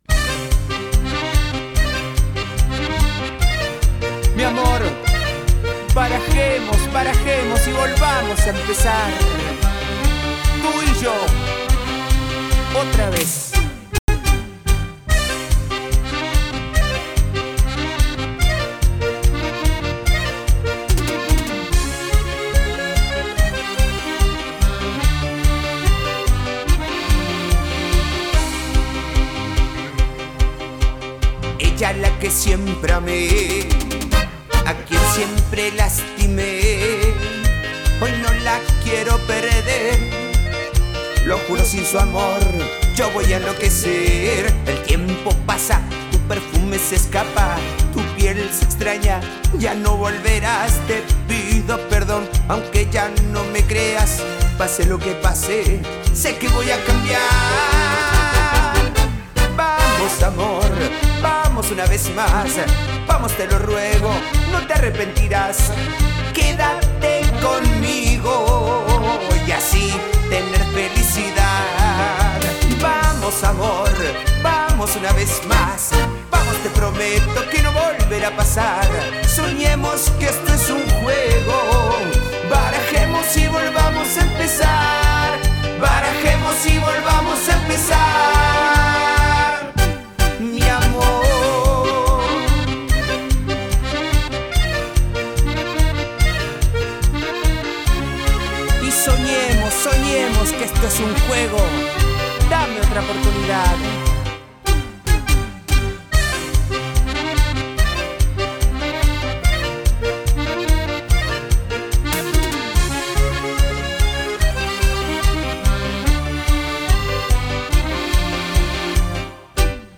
característico, y otro más para el lado del merengue.
Teclados y secuencias
Timbales
Bajo
Congas
Acordeón
Tambora
Cantante